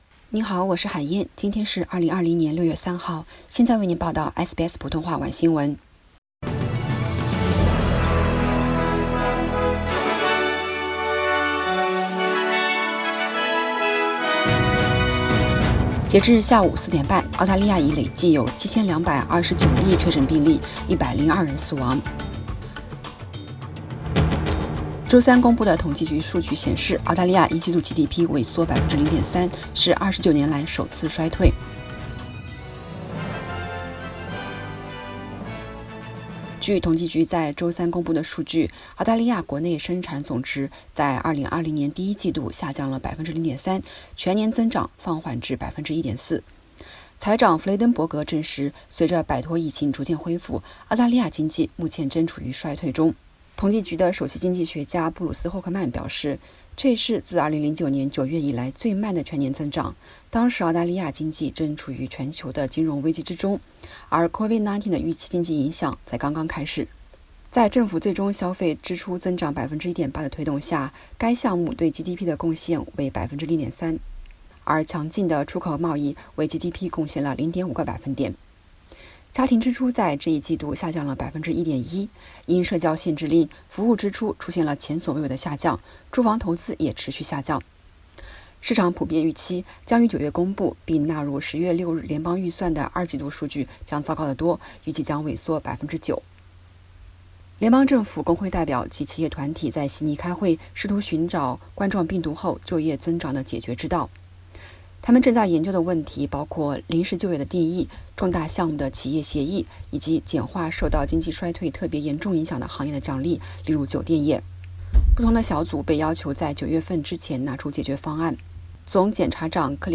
SBS晚新闻（6月3日）
SBS 普通话电台